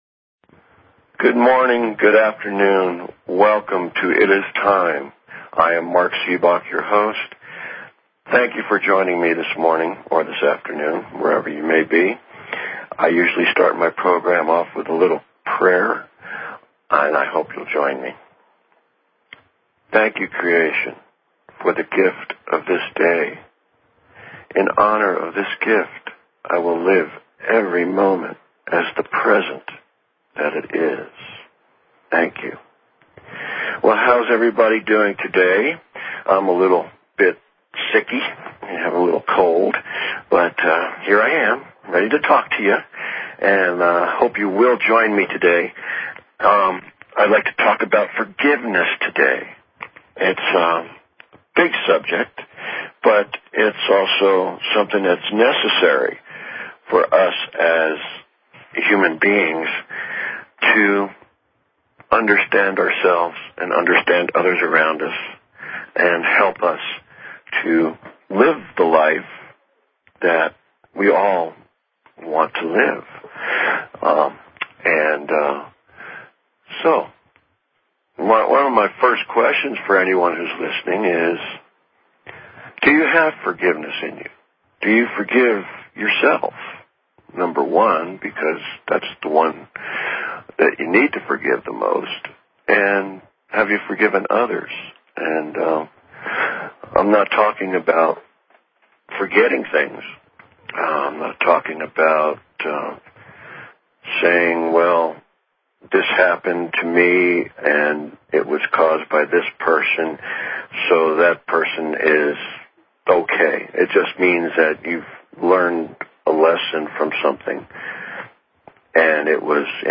Talk Show Episode, Audio Podcast, It_IS_Time and Courtesy of BBS Radio on , show guests , about , categorized as